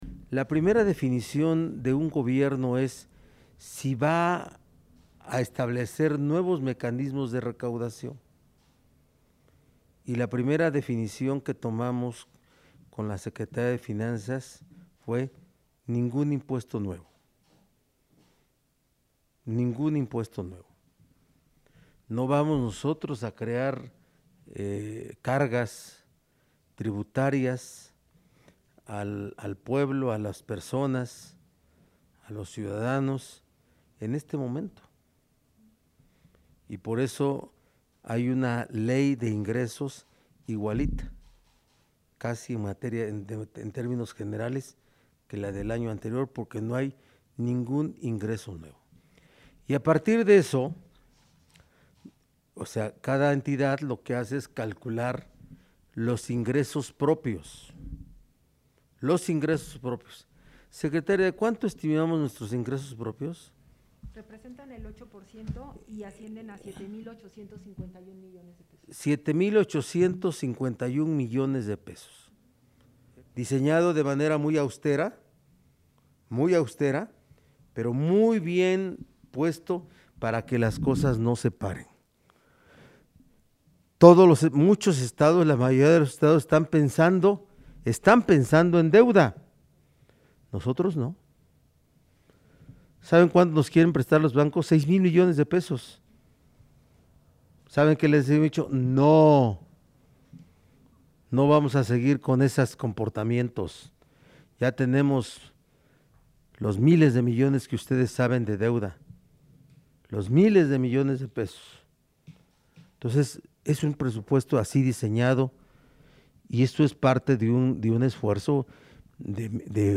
En videoconferencia de prensa en Casa Aguayo, el gobernador Miguel Barbosa Huerta comentó que las iniciativas de leyes de Ingresos y Egresos 2021 presentan un proyecto austero, pero ordenado para no afectar la operación de los programas sociales ni de aquellas acciones que generan un bien a la población.